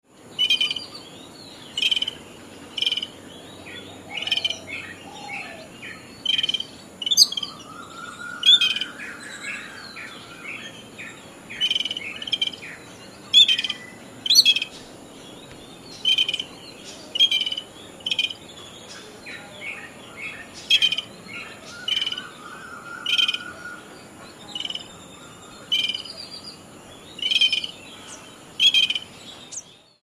Contact calls, to maintain contact between a pair, especially when they are some distance apart, and within members of a family or flock, e.g. made by the Yellow-billed Babbler.
Yellow-billed Babbler – soft calls
Yellow-billed-Babbler_soft-calls.mp3